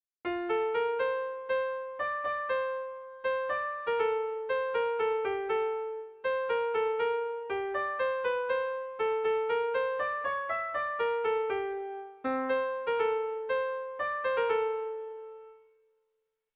Kontakizunezkoa
ABDE